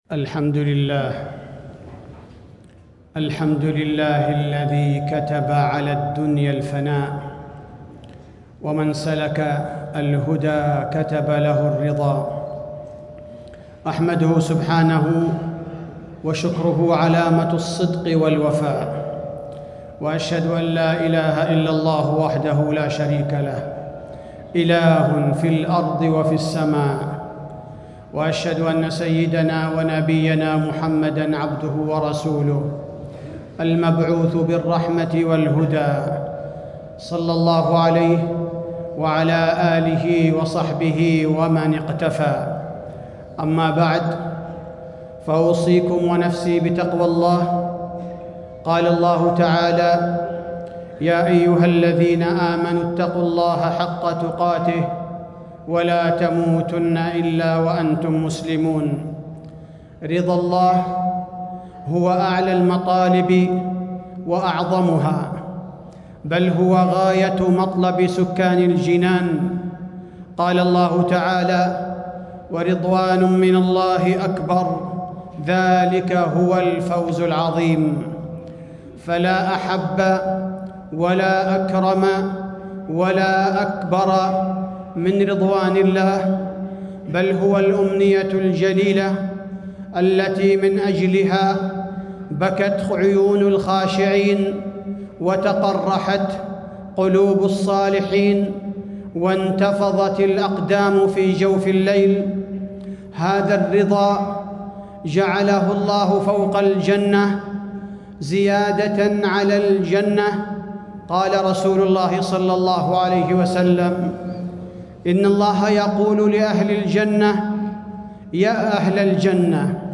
تاريخ النشر ١ جمادى الأولى ١٤٣٦ هـ المكان: المسجد النبوي الشيخ: فضيلة الشيخ عبدالباري الثبيتي فضيلة الشيخ عبدالباري الثبيتي كيف ينال العبد رضا الله